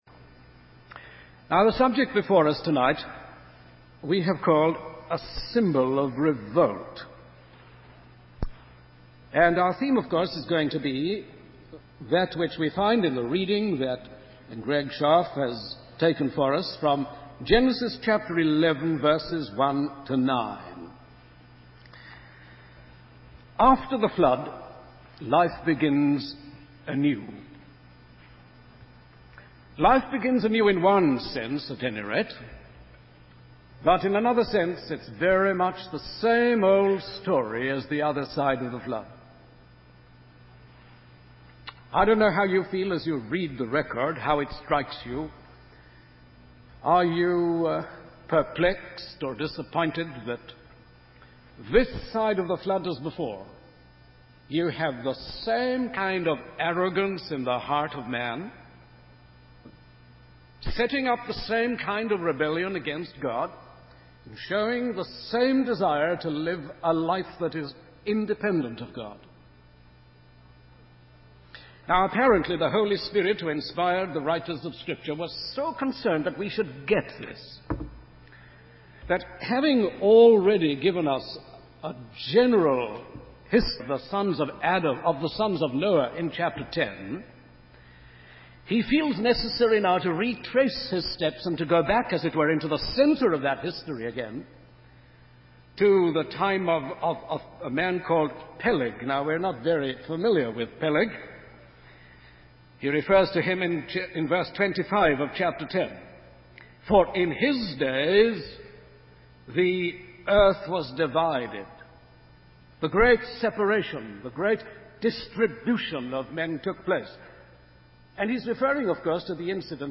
In this sermon, the preacher emphasizes the greatness of God and His sovereignty. He warns about the spiritual influence of Satan that unites sinners in an evil course, using the story of Babel as an example. The preacher also discusses God's judgment and how it can take different forms.